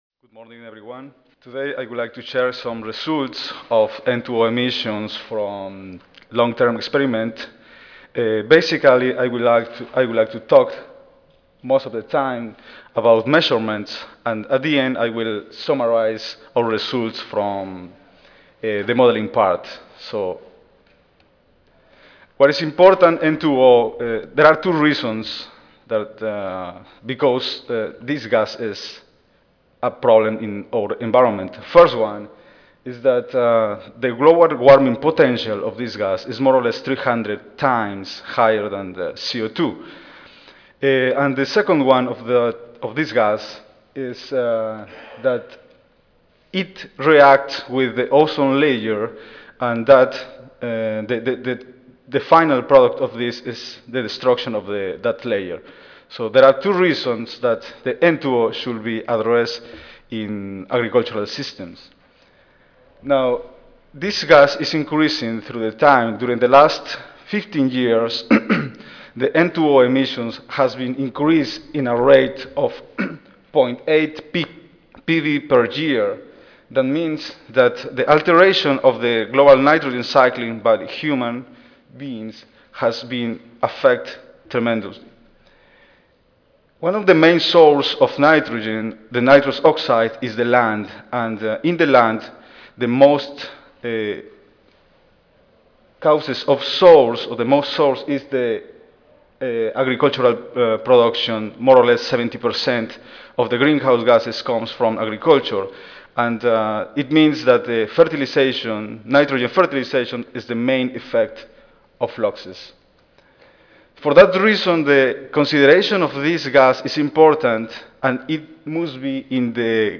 University of New Hampshire Audio File Recorded presentation